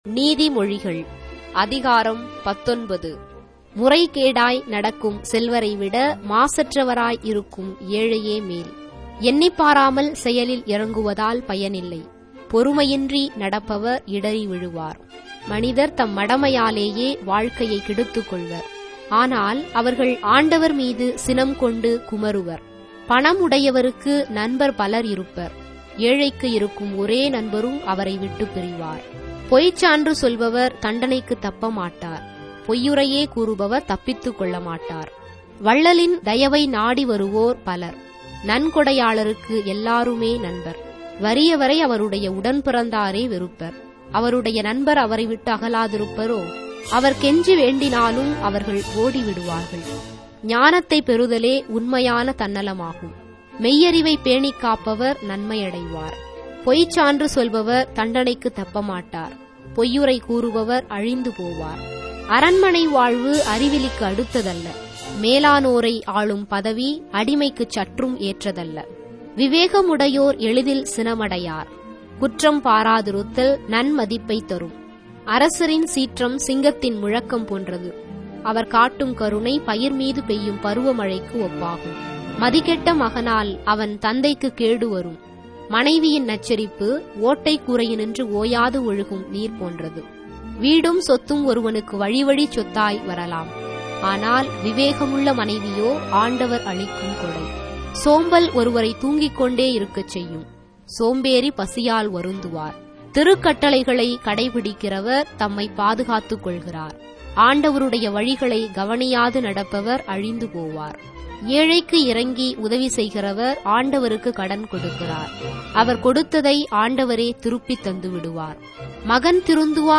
Tamil Audio Bible - Proverbs 7 in Ecta bible version